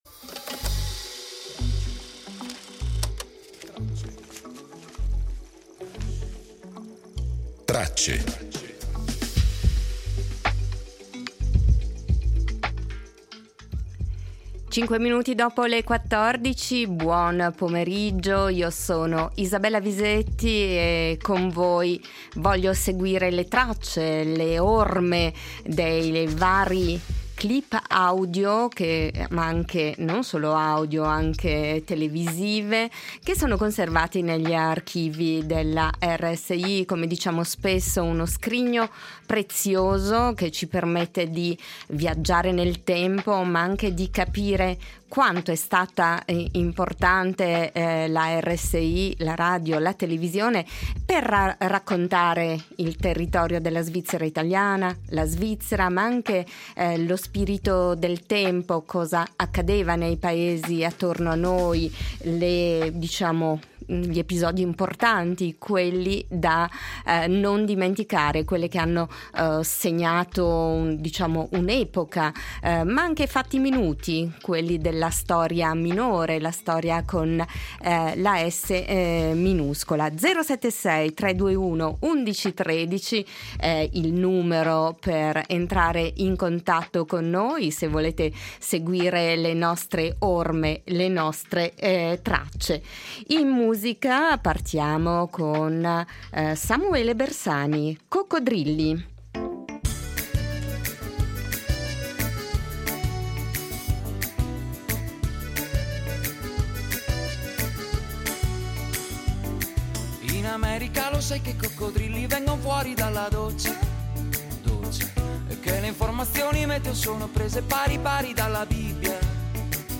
Estratti d’archivio